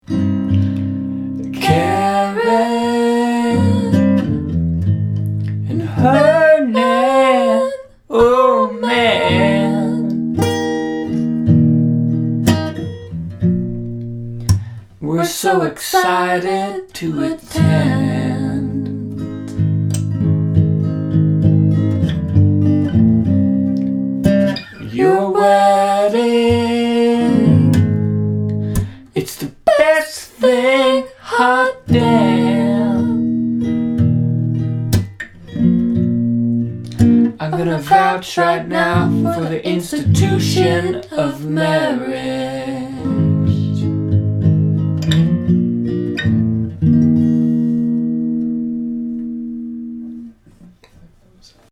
verse: Cm, Aflat, Eflat, B, A, Aflat (x2)